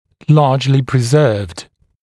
[‘lɑːʤlɪ prɪ’zɜːvd][‘ла:джли при’зё:вд]в значительной степени сохраненный